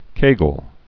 (kāgəl)